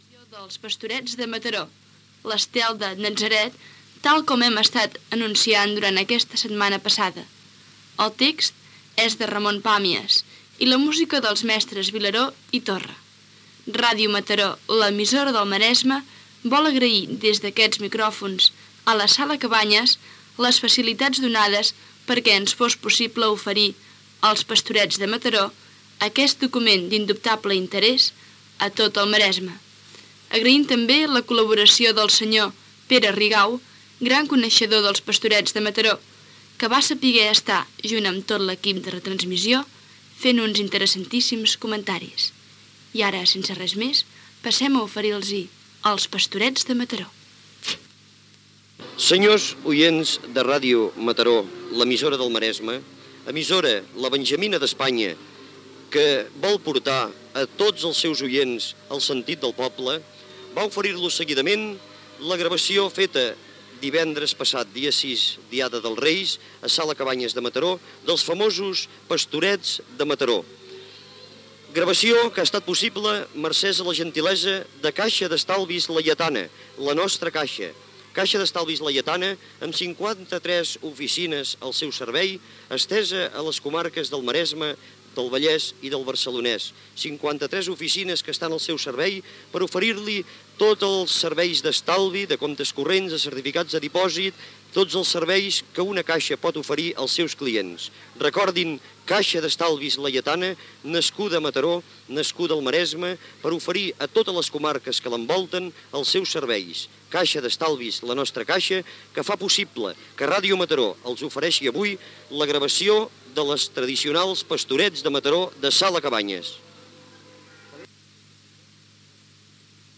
La representació es va fer el 6 de gener de 1978 a la Sala Cabanyes de Mataró.